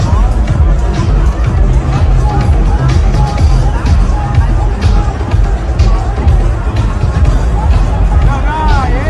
Alain Berset bekommt eine Bierdusche an der Streetparade